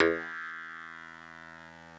genesis_bass_028.wav